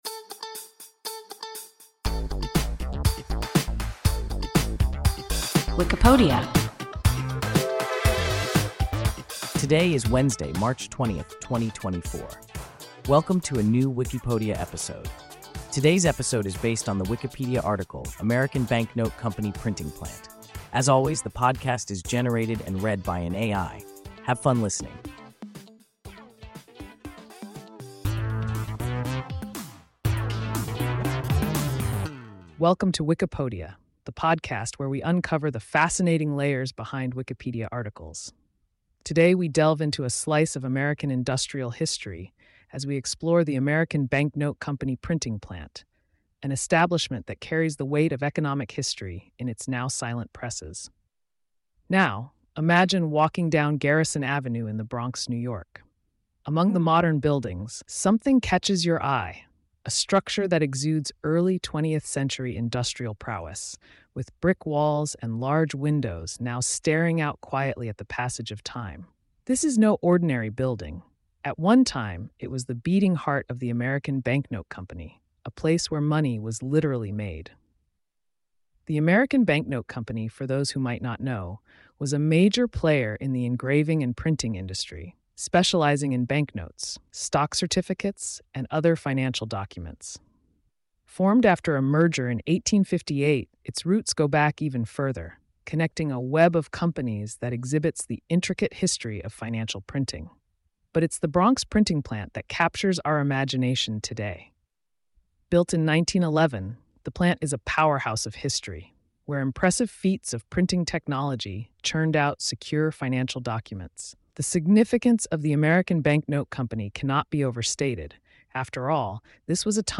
American Bank Note Company Printing Plant – WIKIPODIA – ein KI Podcast